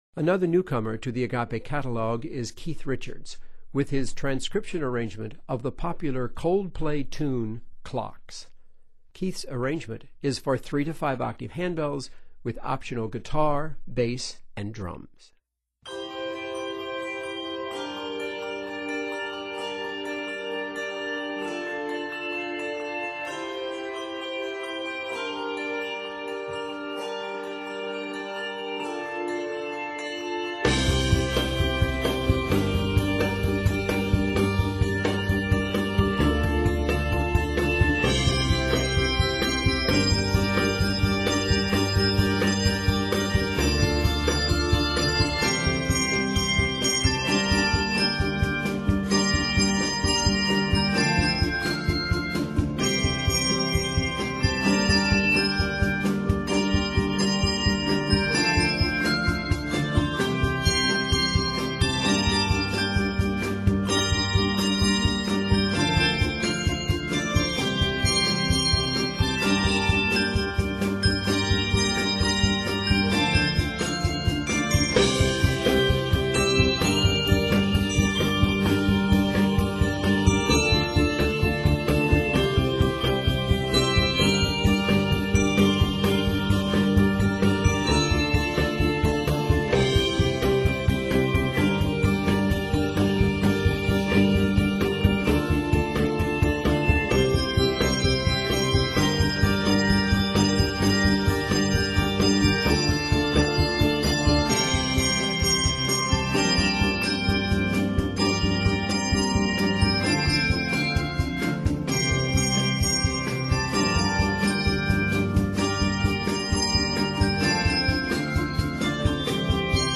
The Rhythm Parts include Guitar, Bass & Drums.